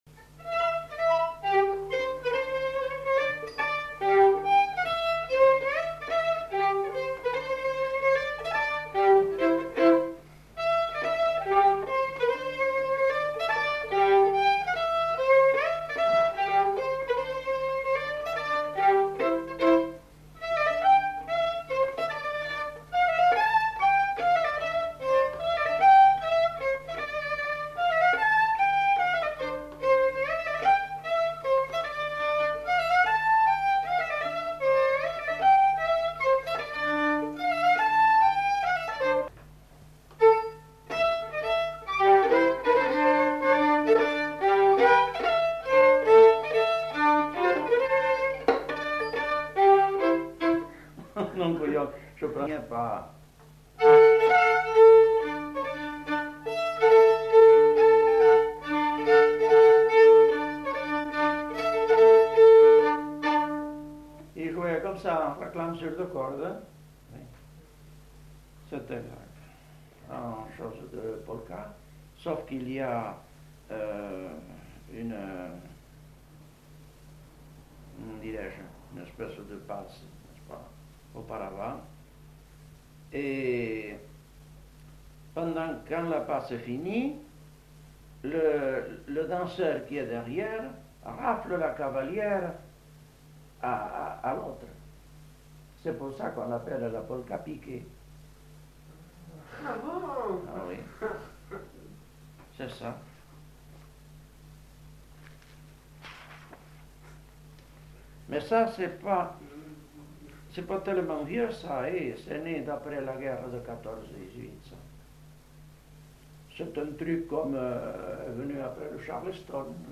Aire culturelle : Gabardan
Genre : morceau instrumental
Instrument de musique : violon
Danse : mazurka